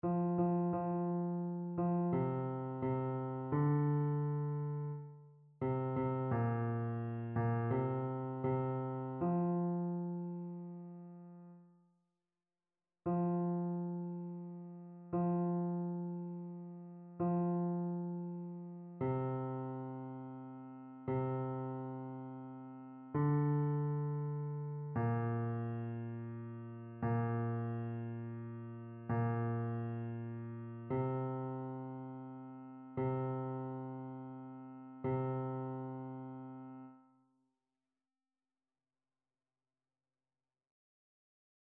Chœur
Basse
annee-a-temps-du-careme-4e-dimanche-psaume-22-basse.mp3